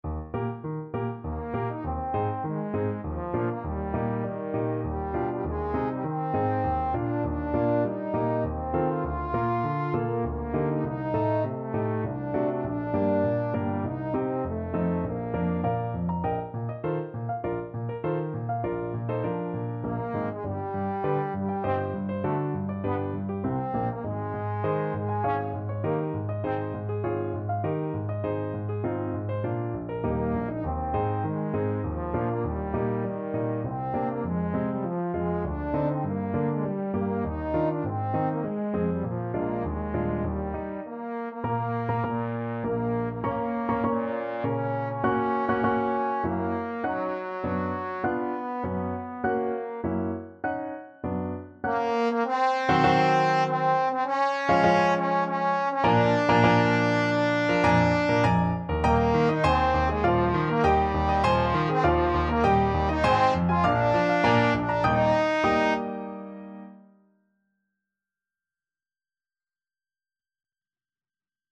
Trombone
Eb major (Sounding Pitch) (View more Eb major Music for Trombone )
~ = 100 Tempo di Menuetto
3/4 (View more 3/4 Music)
Bb3-F5
Classical (View more Classical Trombone Music)